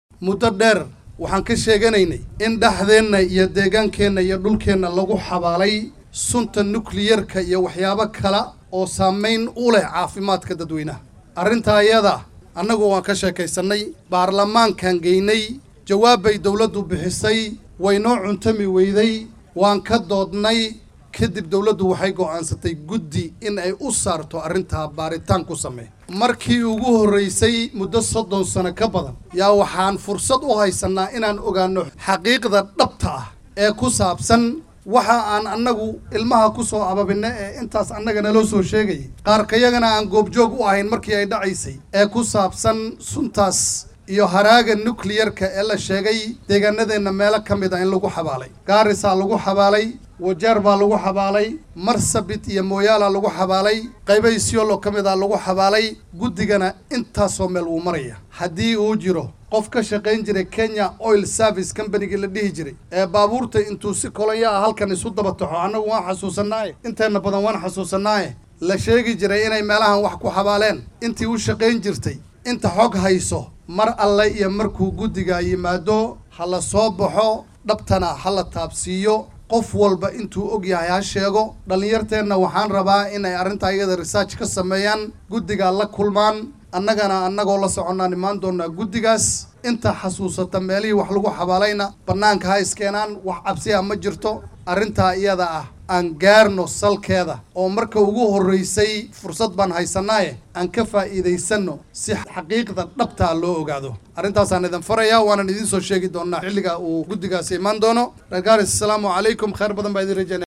DHAGEYSO:Xildhibaan Maxamad Caddow oo ka hadlay nukliyeer la sheegay in gobolka WQ lagu xabaalay